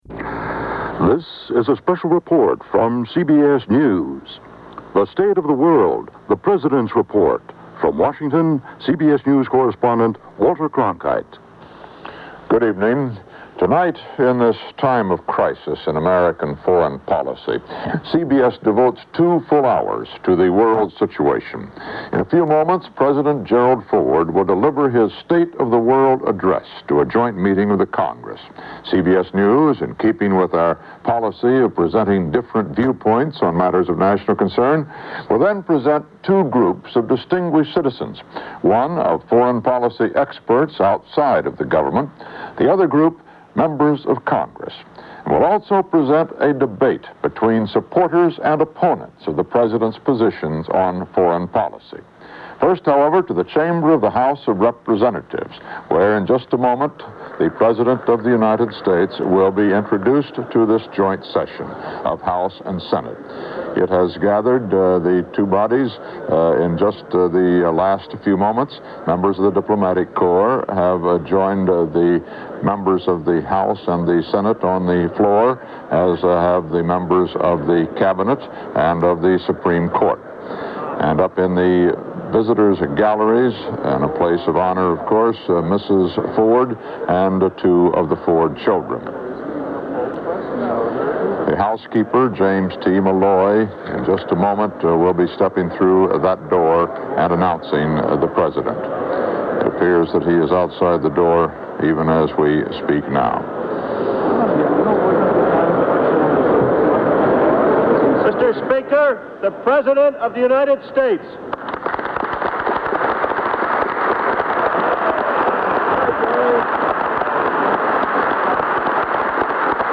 Gerald Ford gives a State of the World address to both houses of Congress with commentary by newsmen and political figures.
Broadcast on CBS-TV, April 10, 1975.